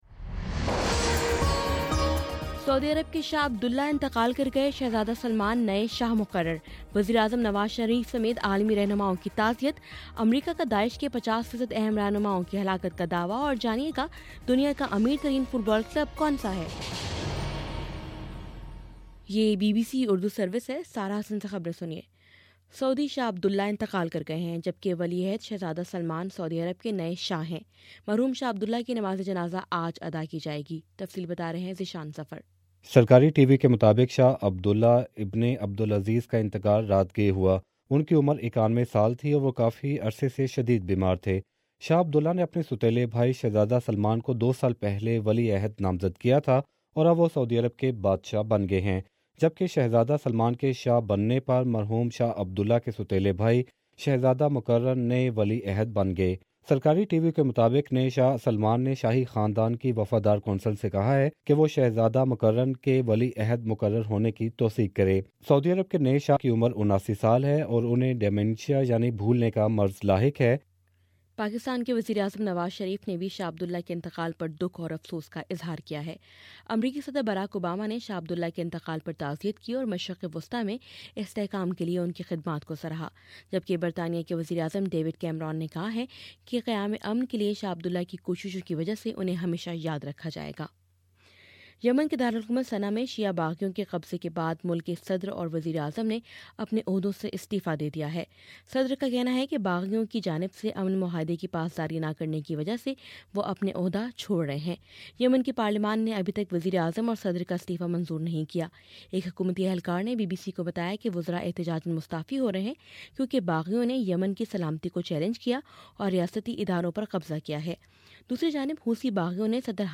جنوری 23: صبح نو بجے کا نیوز بُلیٹن